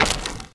pedology_turf_footstep.5.ogg